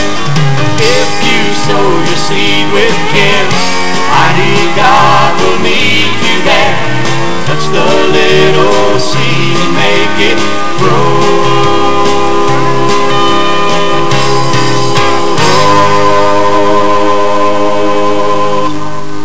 is a singer/songwriter and plays piano.
He also plays bass guitar for the group.
sings lead and harmony vocals